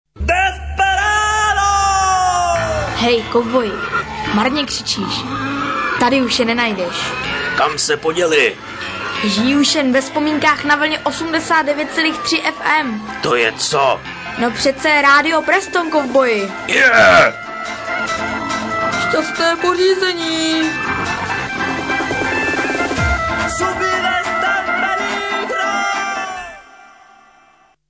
Jingle...